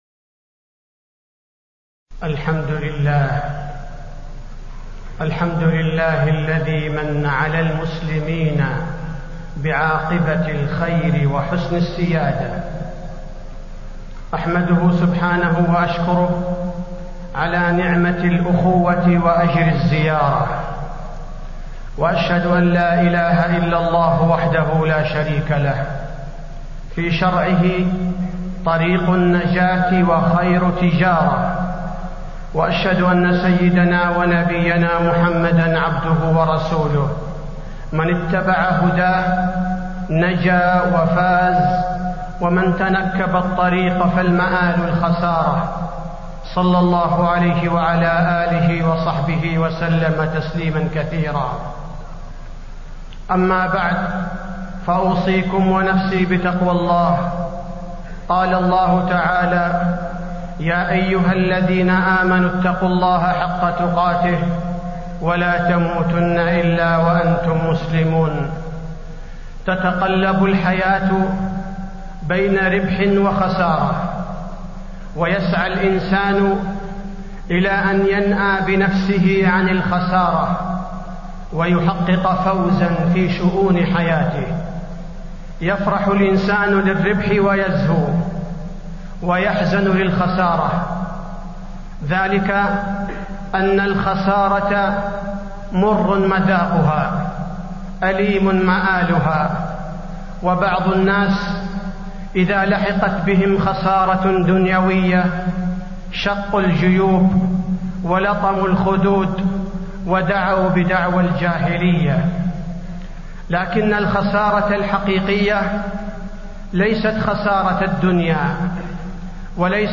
تاريخ النشر ٣٠ جمادى الآخرة ١٤٣٤ هـ المكان: المسجد النبوي الشيخ: فضيلة الشيخ عبدالباري الثبيتي فضيلة الشيخ عبدالباري الثبيتي الخسارة الحقيقية